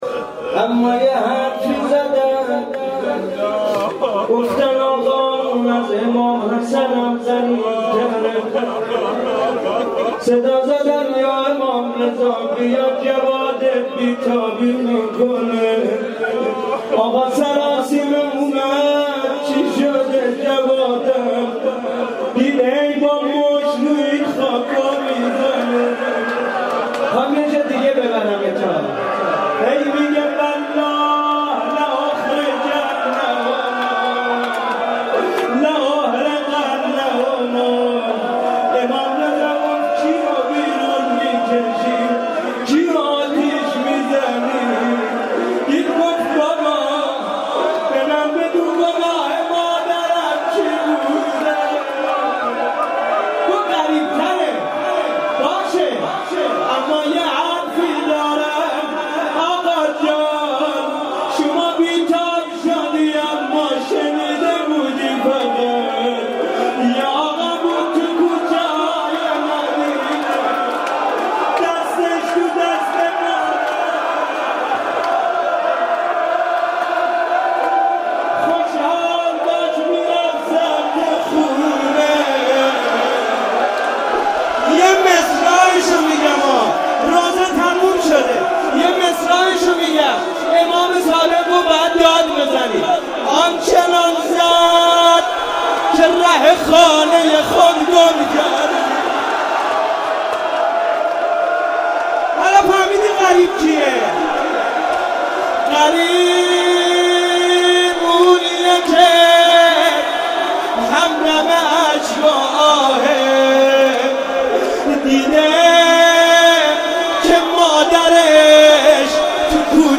روضه
روضه مناسبت : شب نهم رمضان سال انتشار